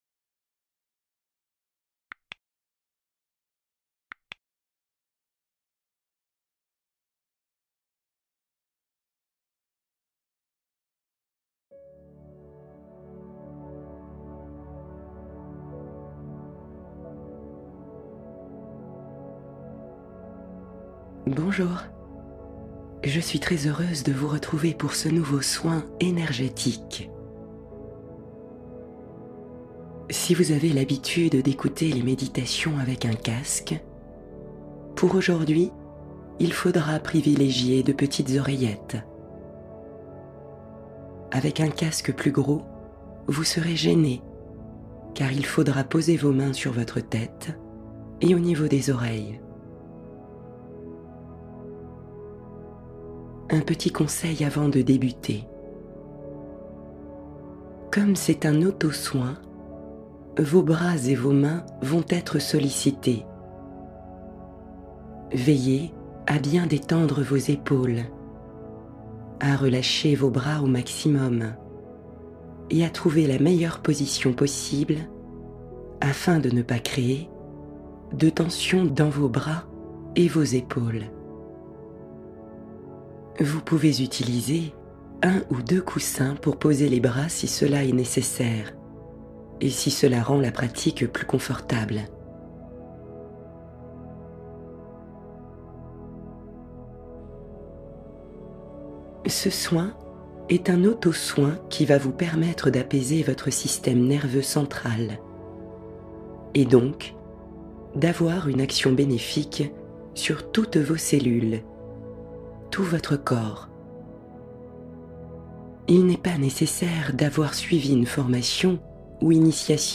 Histoire du soir rassurante — Le rituel apaisant qui unit enfants et parents